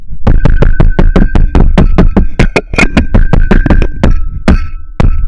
두드리는03.ogg